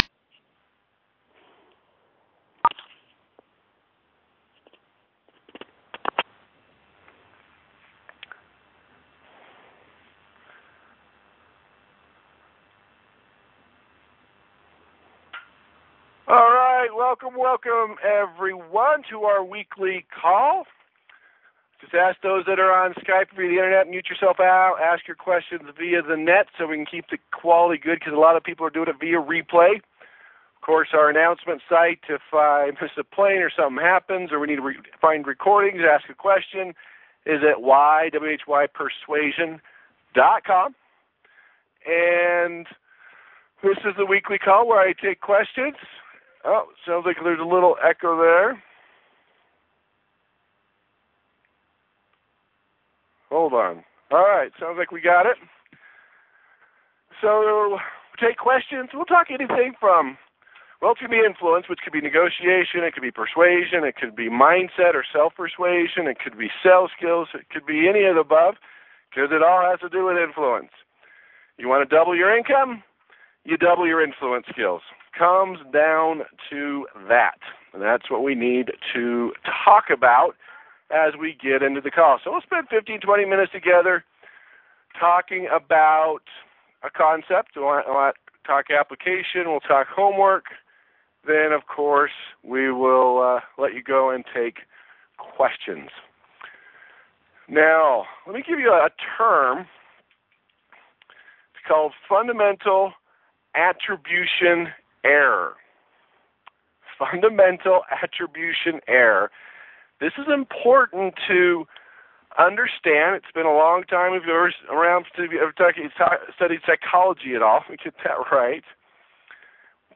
‹ Free internet traffic Gate keeper 3 › Posted in Conference Calls